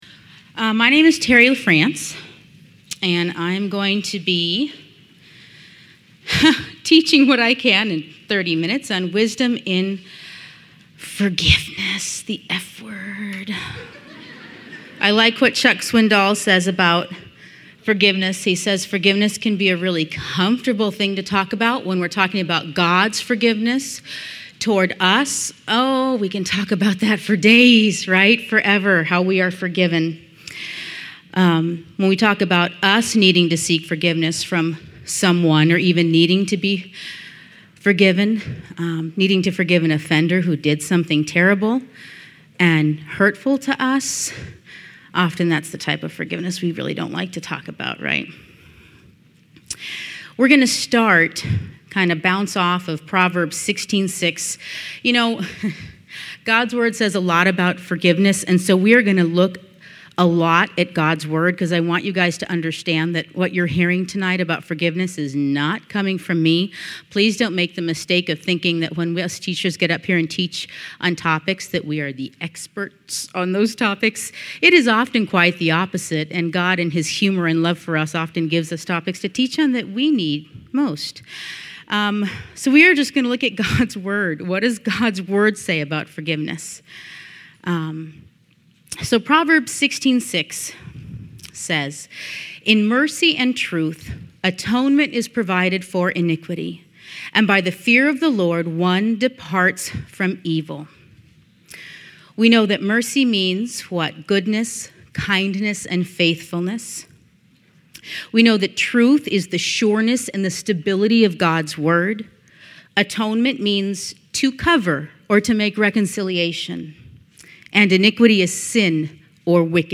Women's Retreat 2015: Pearl's of Wisdom